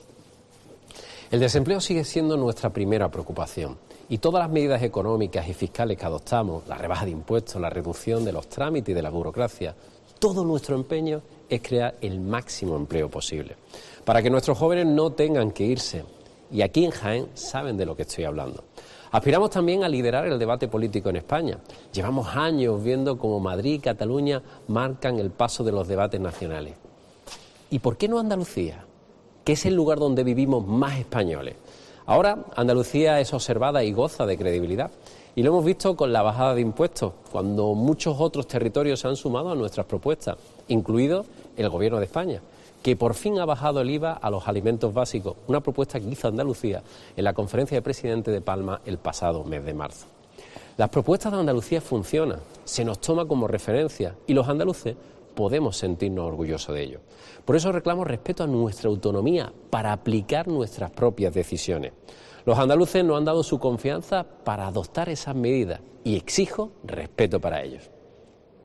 Mensaje de fin de año
El presidente de la Junta de Andalucía formula, en su mensaje de fin de año, la aspiración de "liderar el debate político" es España
Moreno ha hecho este llamamiento durante su tradicional mensaje de fin de año, que este 2022 ha retrasado a la misma Nochevieja y que ha elegido realizar desde la cooperativa olivarera Picualia, en Bailén (Jaén), como reconocimiento a uno de los sectores económicos, el agrícola, que más directamente está sufriendo los efectos de la sequía y el encarecimiento de los costes de producción.